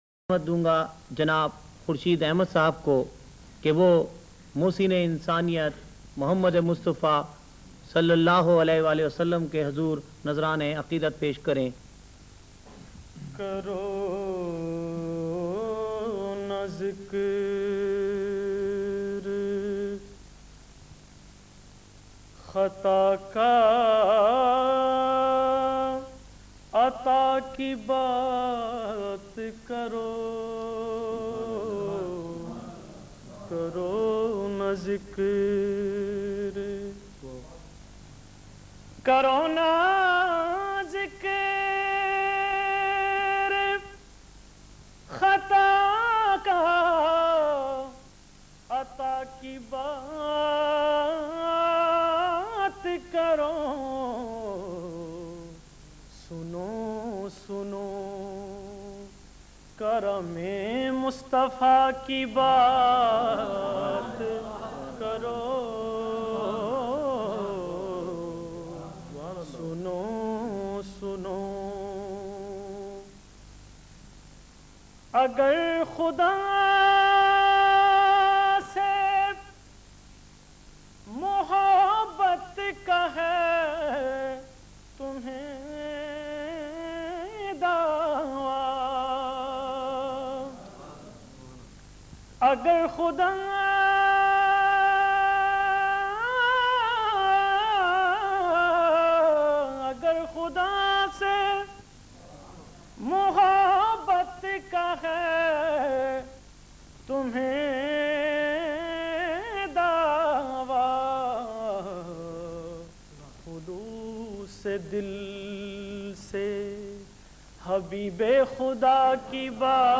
in best audio quality
naat sharif